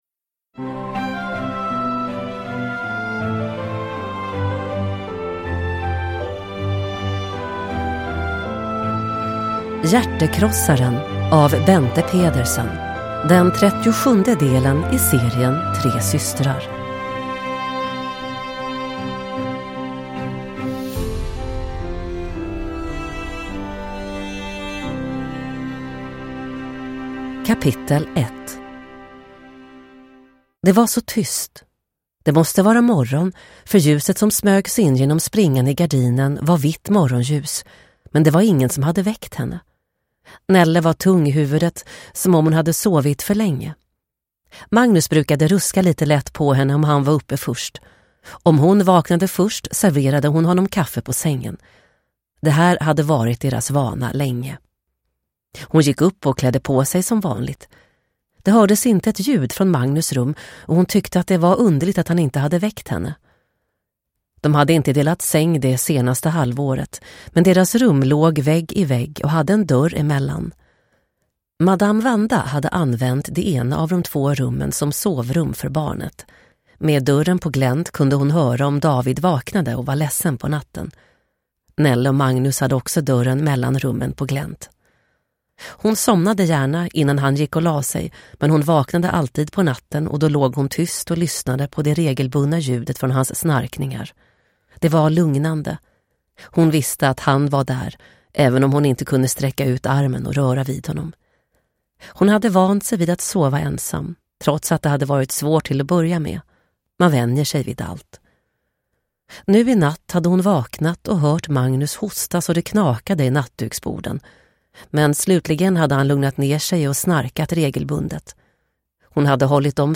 Hjärtekrossaren – Ljudbok – Laddas ner